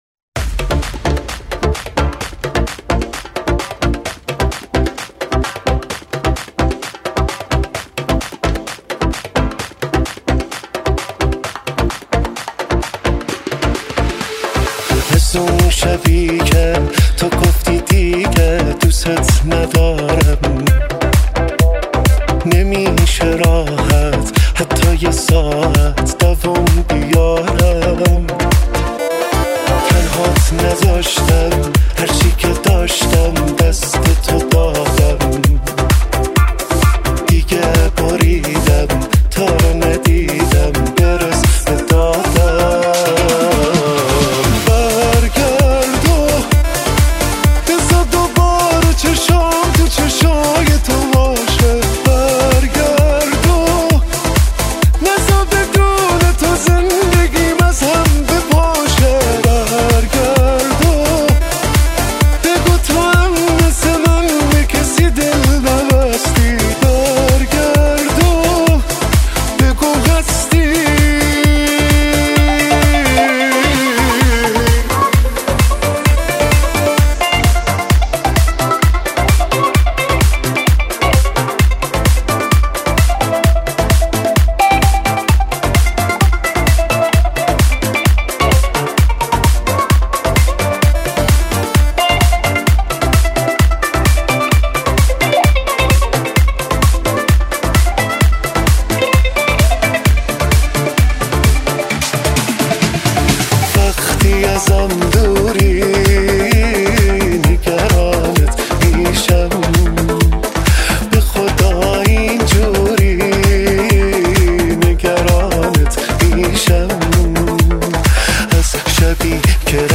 мусиқӣ